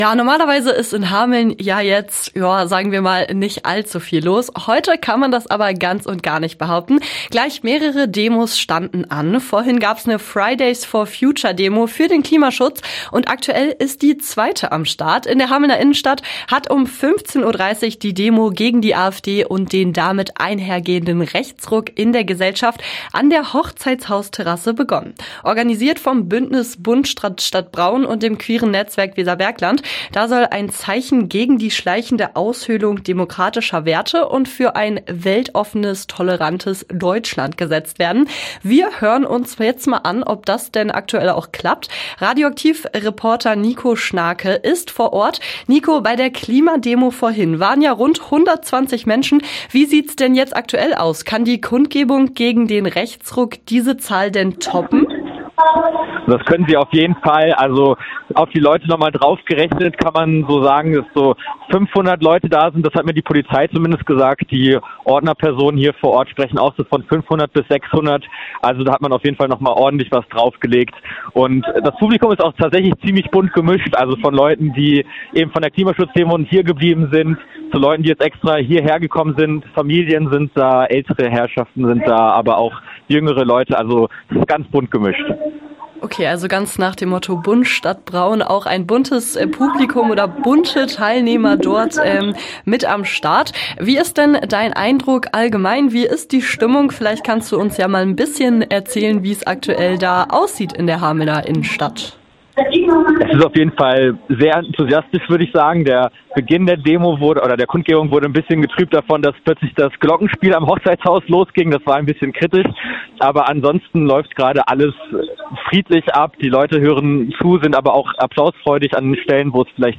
Hameln: Live von der Demo gegen den Rechtsruck in der Gesellschaft